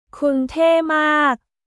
クン テー マーク！